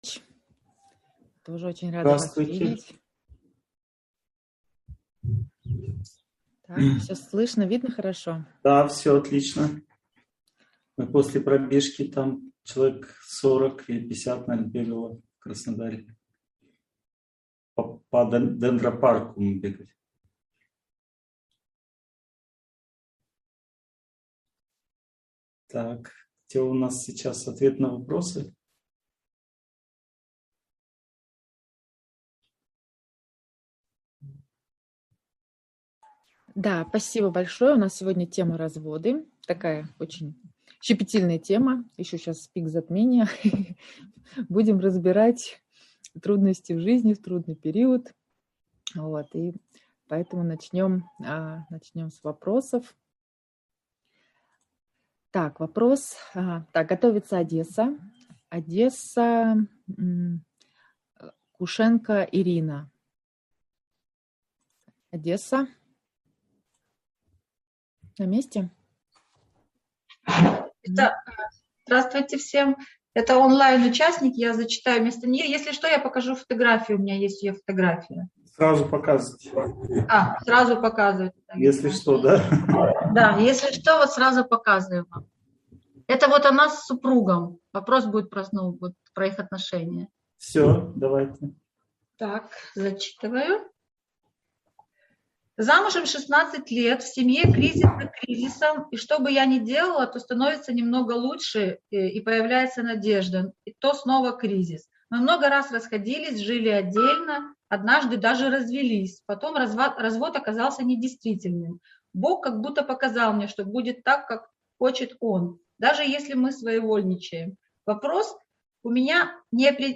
Разводы (онлайн-семинар, 2021)